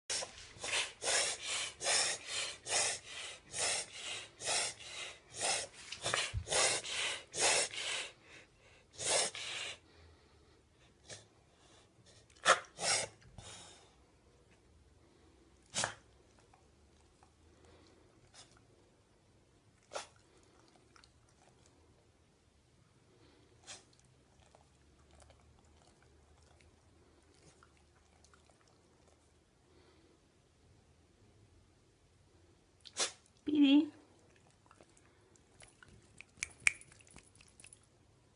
In effetti lo starnuto inverso potrebbe essere considerato come una sorta di solletico in gola o nelle vie nasali posteriori.